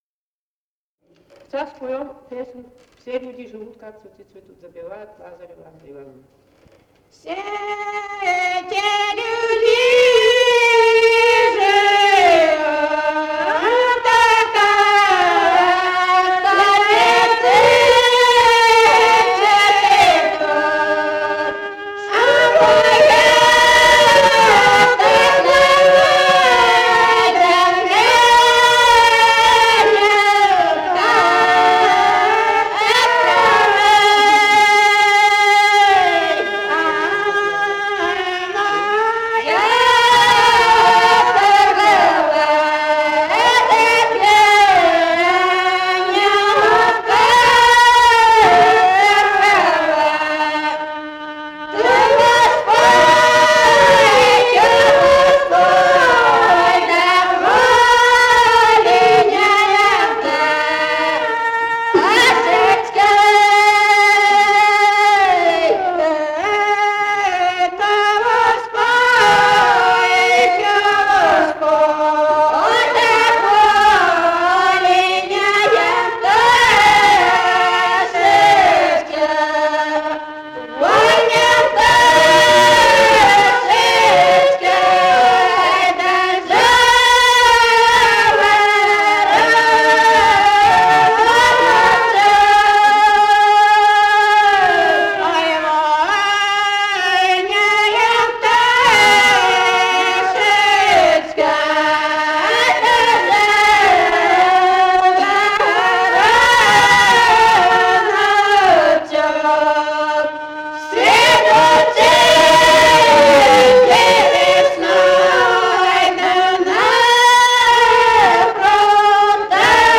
Живые голоса прошлого 008. «Все-те люди живут» (лирическая).